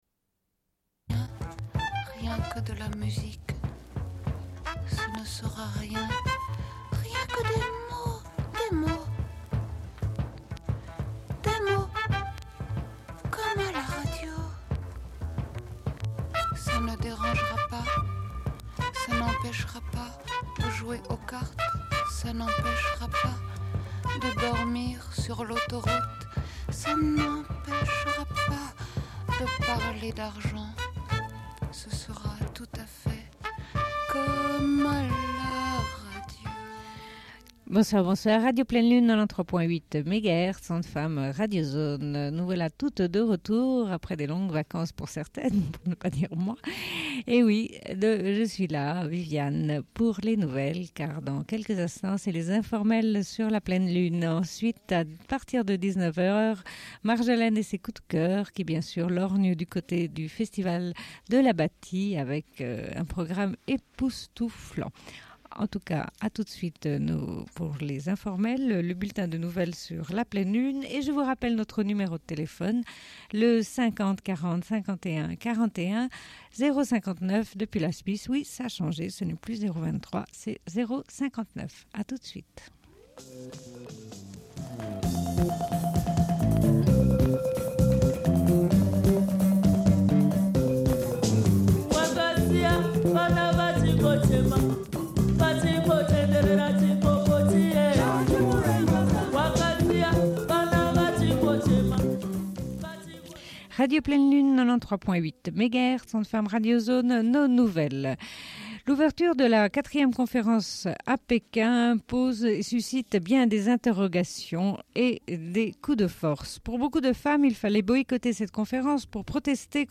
Bulletin d'information de Radio Pleine Lune du 06.05.1995 - Archives contestataires
Une cassette audio, face B29:51